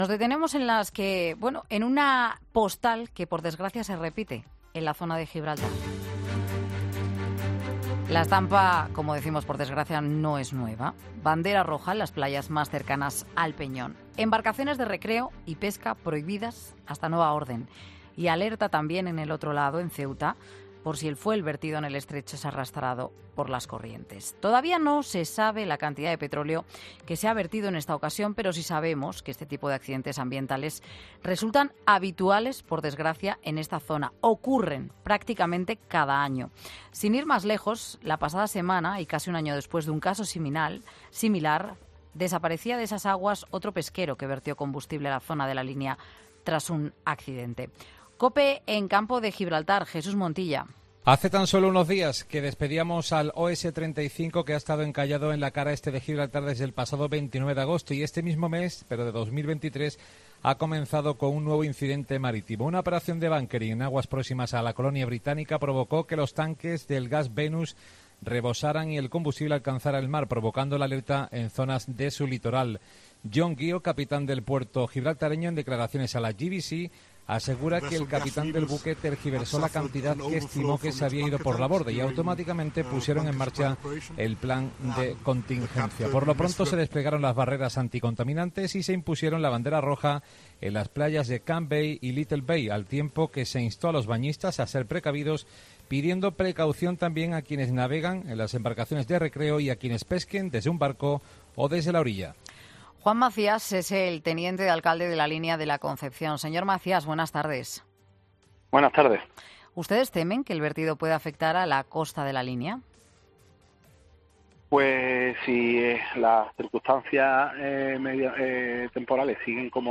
Juan Macías, teniente de alcalde de La Línea de la Concepción, en 'Mediodía COPE'
En 'Mediodía COPE' hemos podido hablar con Juan Macías, el teniente alcalde de La Línea de la Concepción.